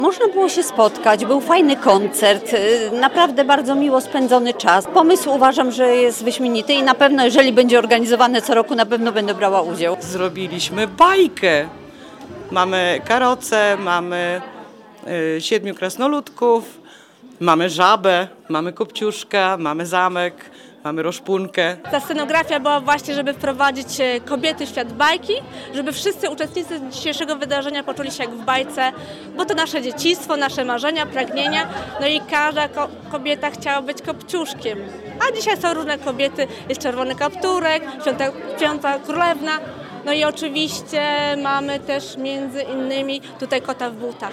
Wyjątkowy wieczór z okazji Dnia Kobiet zorganizował Ośrodek Czytelnictwa i Kultury Gminy Suwałki. Wyjątkowy, bo baśniowy.